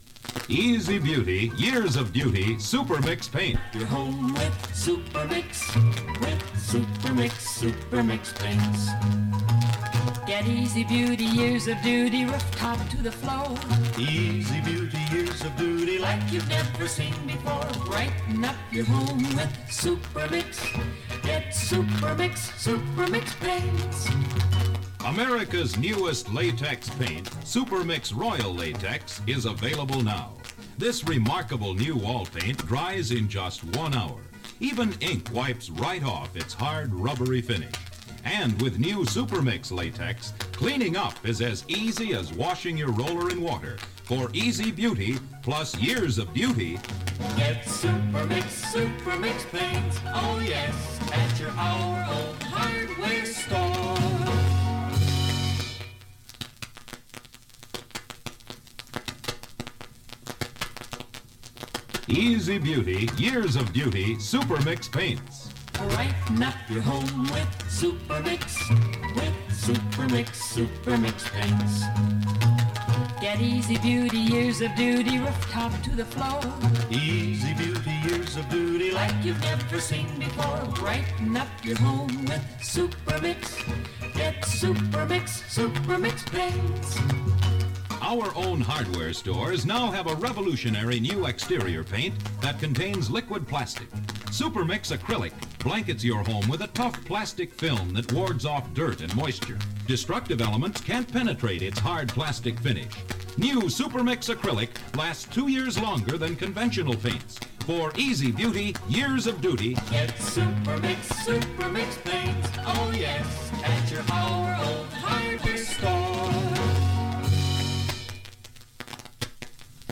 Made from a phonograph record.